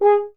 025_FH G#4 SCF.wav